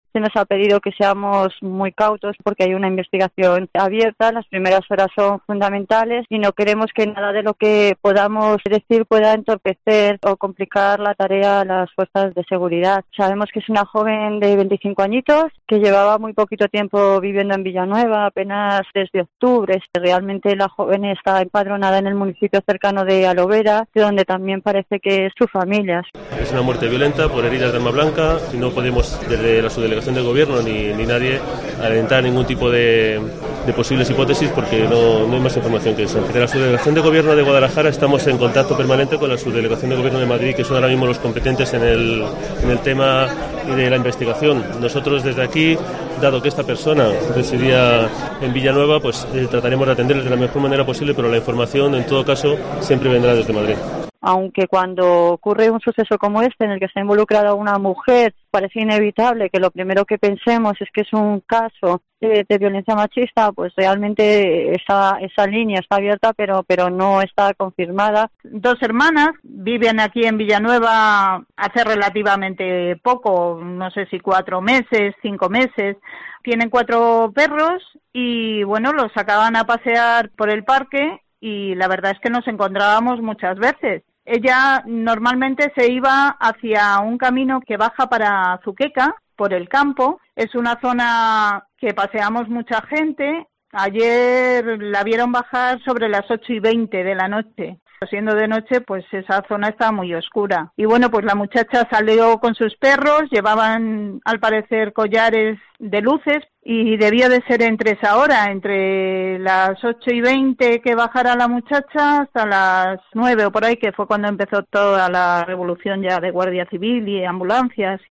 La Alcaldesa de Villanueva, Vanesa Sánchez, ha manifestado que la autoridad que se ha encargado de la investigación les ha pedido no aportar más datos.
Por su parte, el Subdelegado del Gobierno en Guadalajara, Angel Canales, señala que al ser la joven, vecina de Villanueva, van a estar en permanente contacto con las autoridades de Madrid, pero que tampoco pueden aportar más datos.